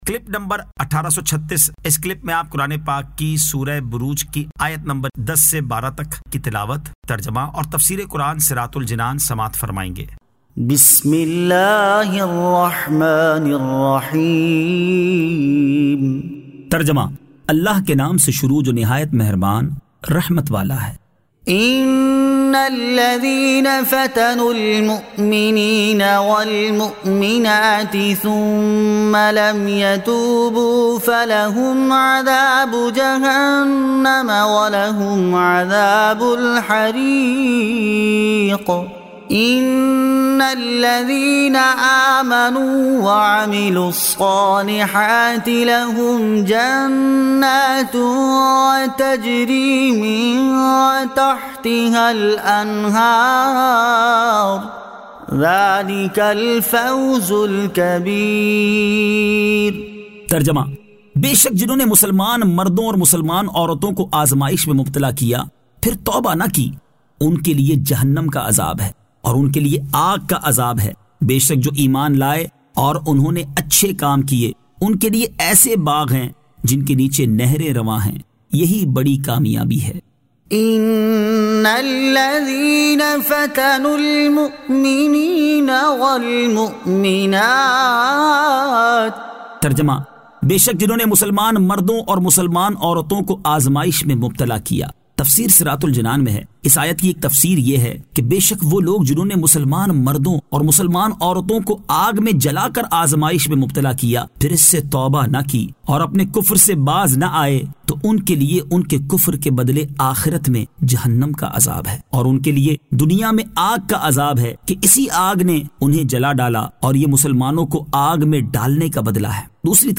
Surah Al-Burooj 10 To 12 Tilawat , Tarjama , Tafseer